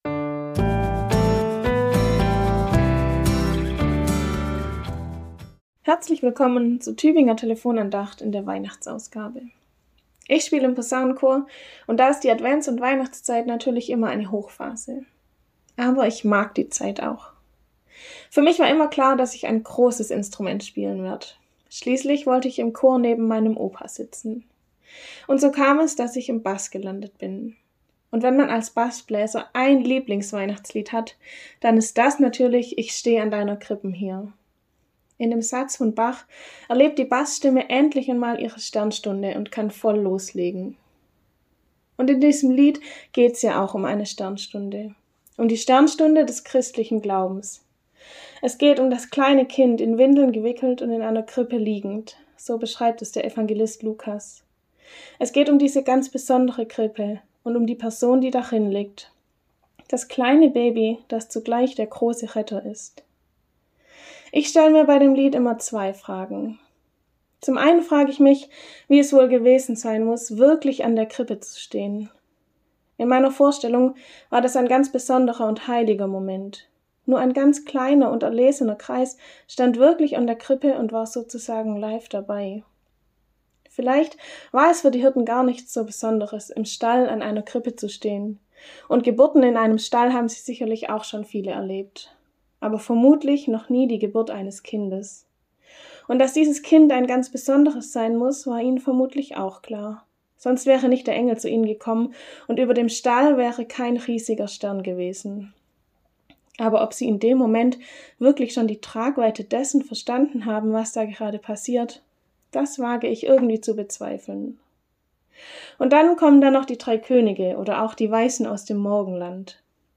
Andacht in der Weihnachtswoche